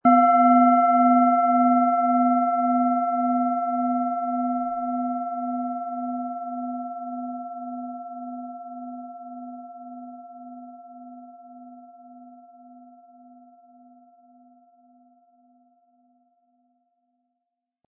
Wie klingt diese tibetische Klangschale mit dem Planetenton Delfin?
Um den Originalton der Schale anzuhören, gehen Sie bitte zu unserer Klangaufnahme unter dem Produktbild.
SchalenformBihar
MaterialBronze